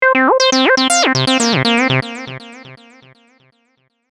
最初からかかっているエフェクトもそのまま鳴らしています。
arp303saw.mp3